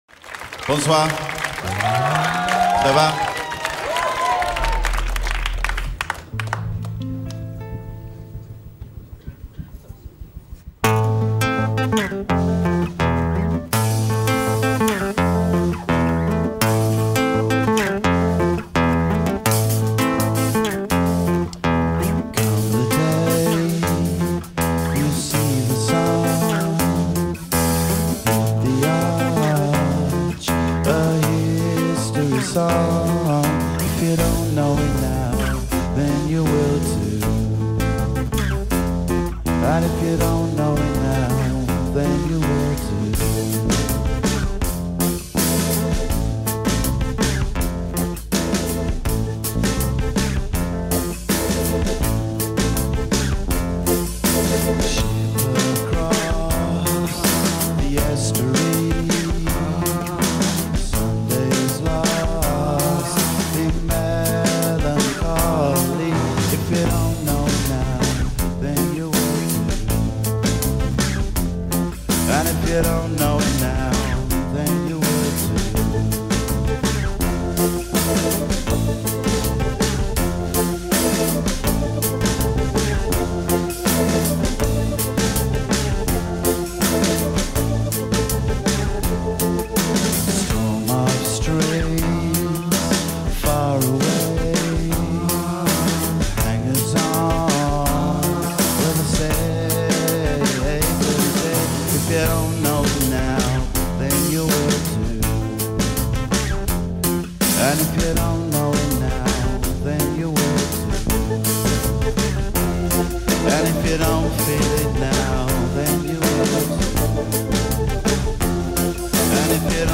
live at St. Denis